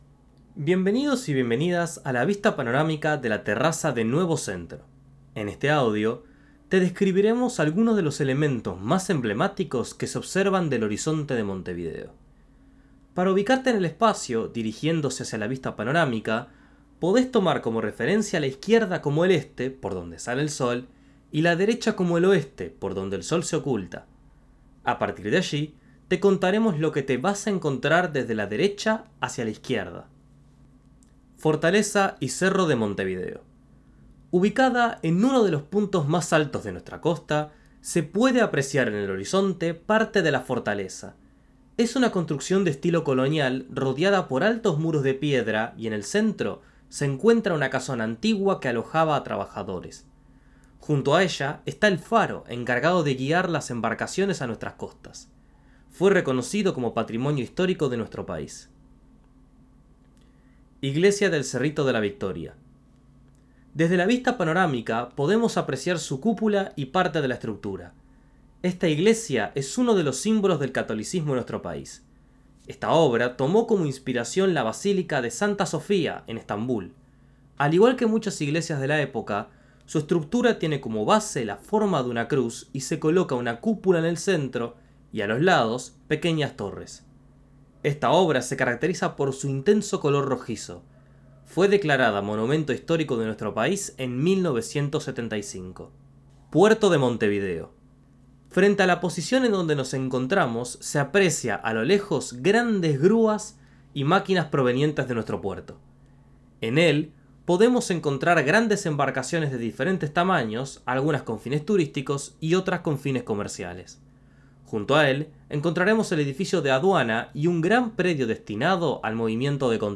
Audiodescripción La terraza